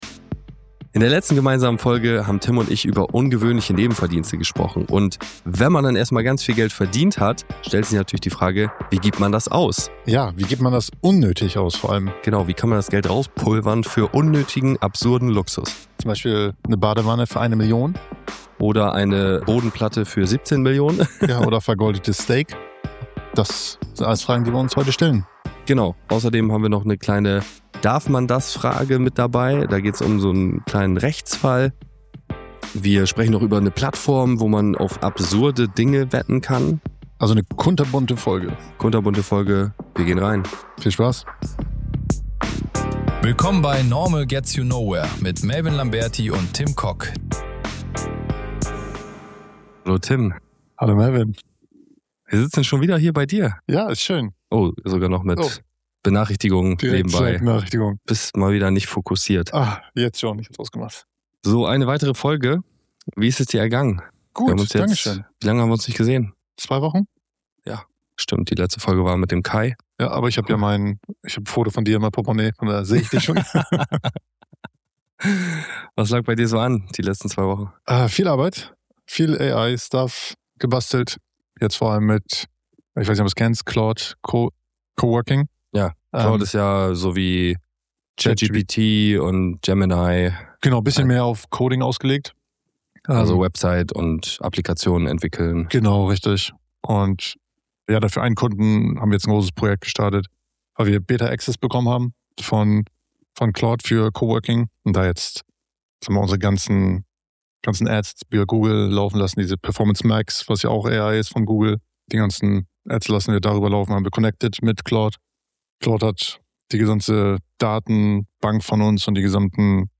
Etwas verschnupft, aber in bester Laune